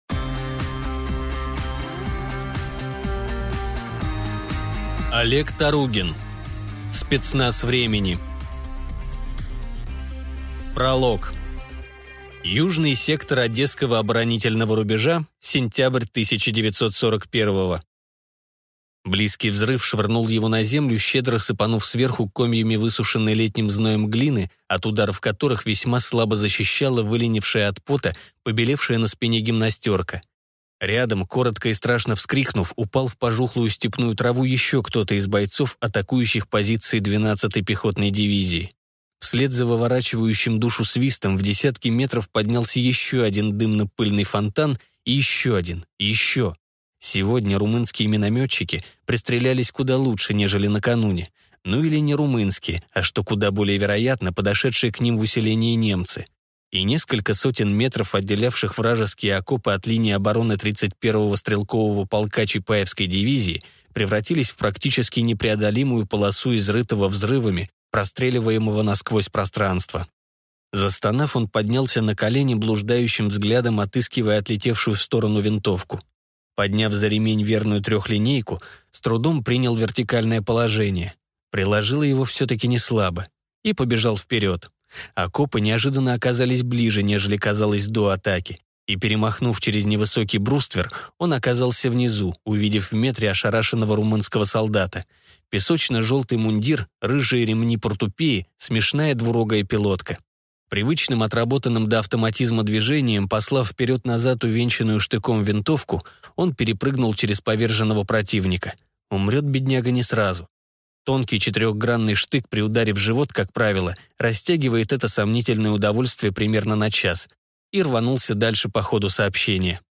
Aудиокнига Спецназ времени